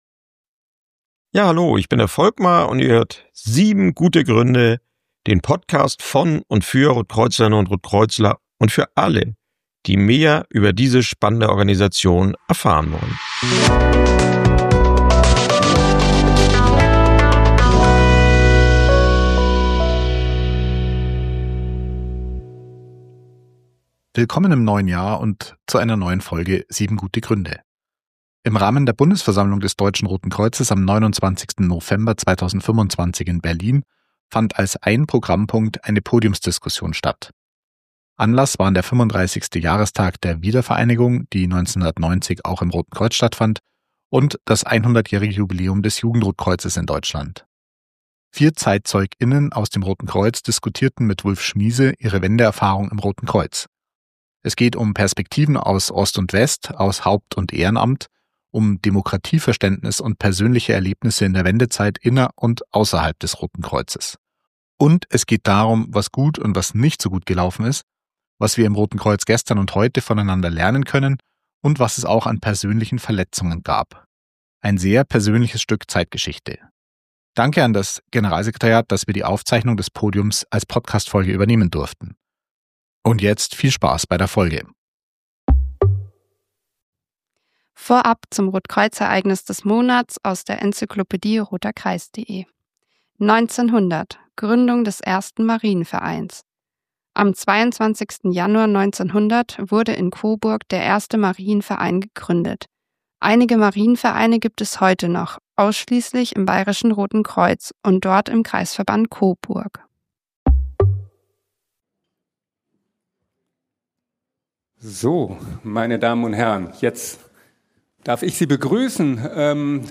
Im Rahmen der Bundesversammlung des Deutschen Roten Kreuzes am 29.11.2025 in Berlin fand als ein Programmpunkt eine Podiumsdiskussion statt.
Vier Zeitzeug*innen aus dem Roten Kreuz diskutierten mit Wulf Schmiese ihre Wendeerfahrung im Roten Kreuz. Es geht um Perspektiven aus Ost und West, aus Haupt- und Ehrenamt, um Demokratieverständnis und persönliche Erlebnisse in der Wendezeit inner- und außerhalb des Roten Kreuzes.
Danke an das Generalsekretariat, dass wir die Aufzeichnung des Podiums als Podcastfolge übernehmen durften!